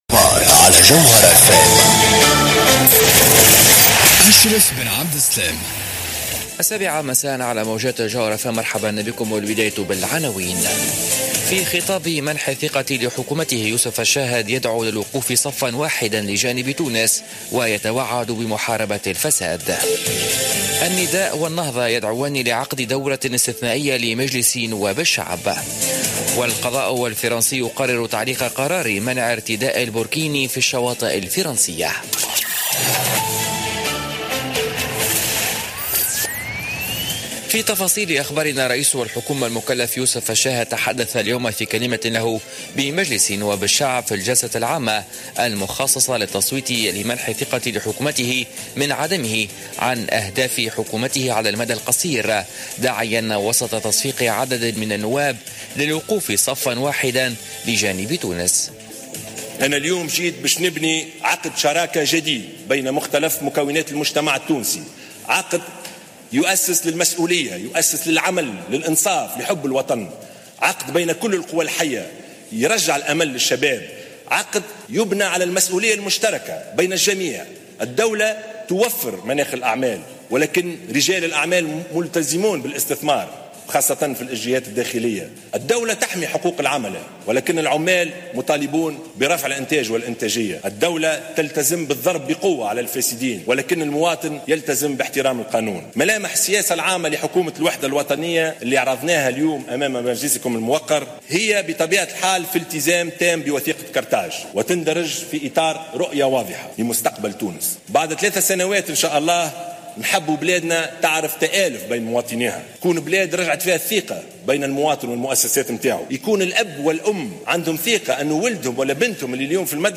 نشرة أخبار السابعة مساء ليوم الجمعة 26 أوت 2016